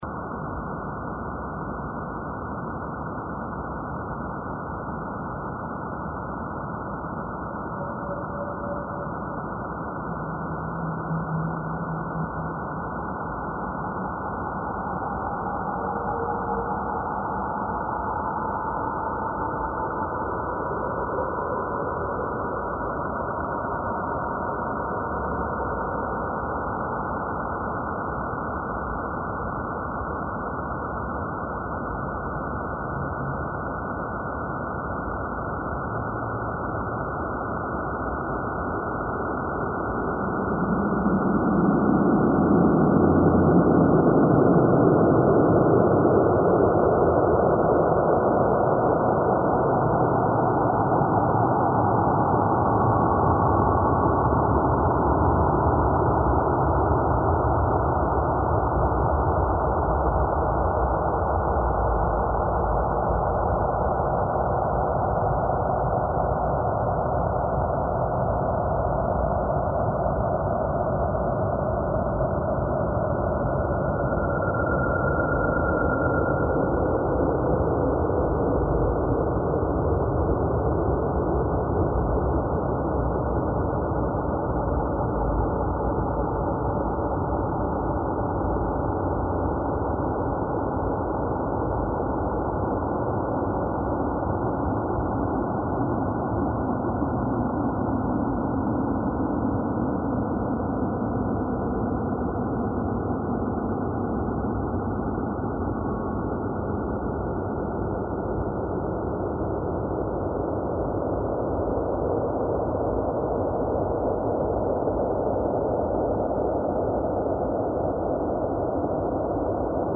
There has been no post-processing and no effects added.
Martian Soundscape : a 3-minute soundscape generated from a picture of Mars.
AudioPaint_MartianSoundscape.mp3